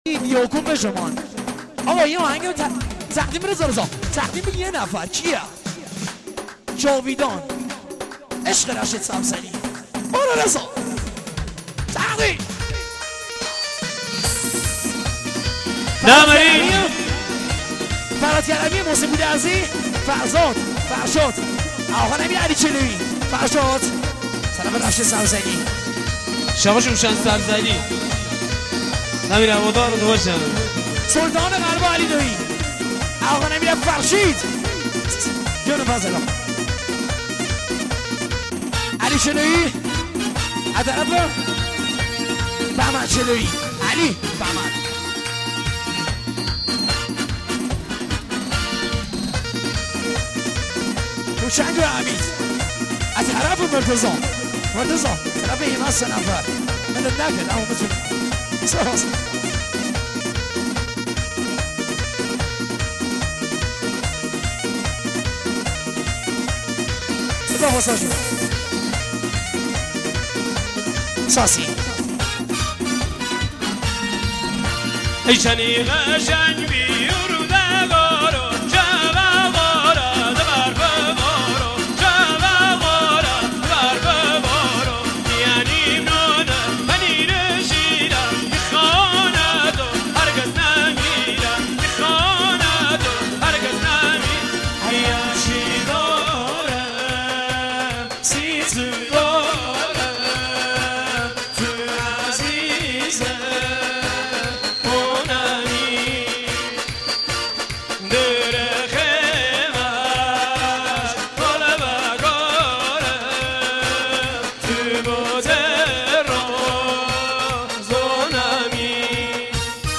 موزیک شاد و رقصیدنی لری
آهنگ شاد و باحال محلی لری